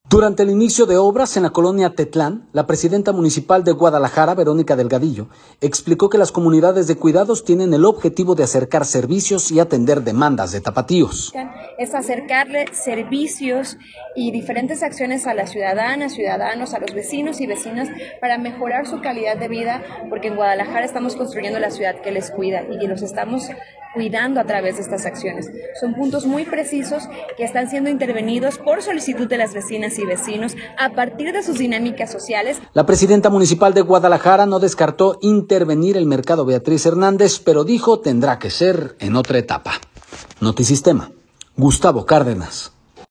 Durante el inicio de obras en la colonia Tetlán, la presidenta municipal de Guadalajara, Verónica Delgadillo, explicó que las comunidades de cuidados tienen el objetivo de acercar servicios y atender las demandas de los tapatíos.